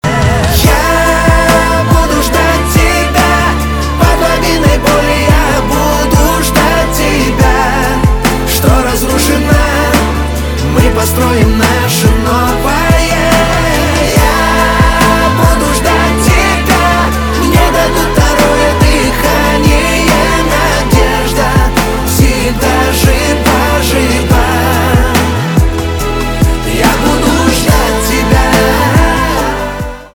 поп
барабаны чувственные